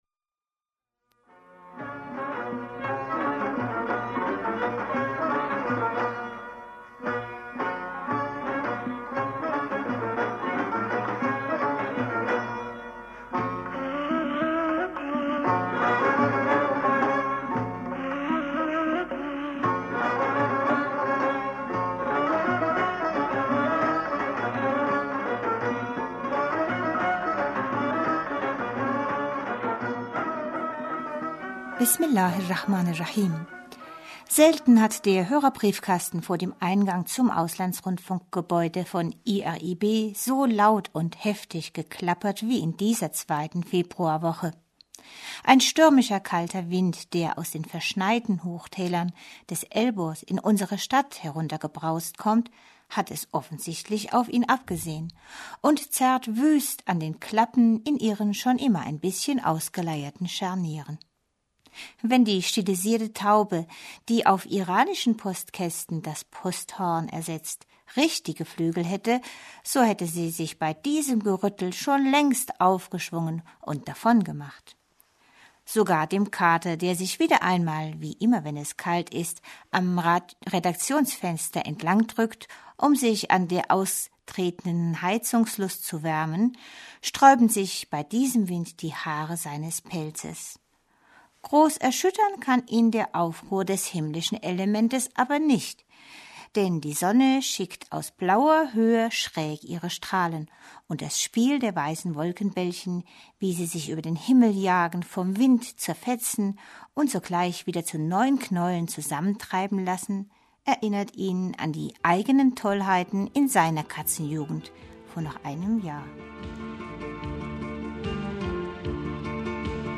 Liebe Hörerfreunde, heute haben wir aus verschiedenen Gründen keine aktuelle Hörerpostsendung, dafür machen wir einen Ausflug in die Vergangenheit. Heute hören Sie eine Sendung von vor 12 Jahren um diese Zeit.